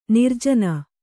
♪ nirjana